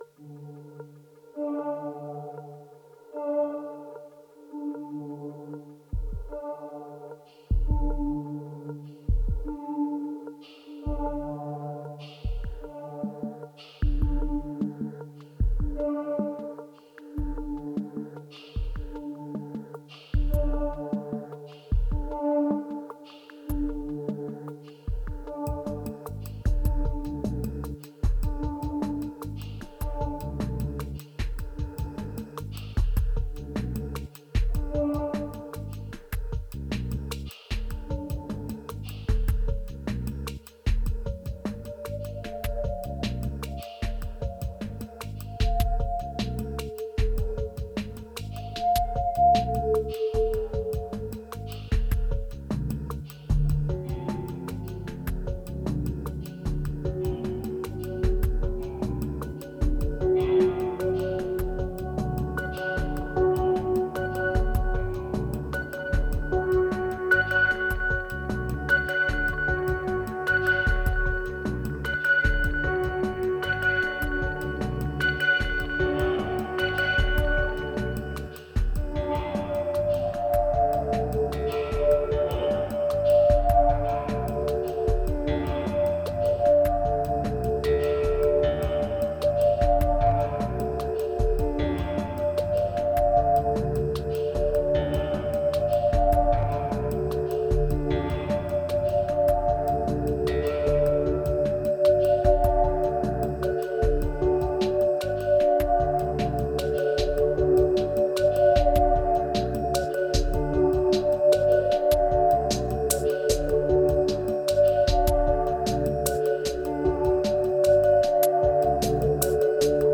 2388📈 - -8%🤔 - 76BPM🔊 - 2017-06-18📅 - -555🌟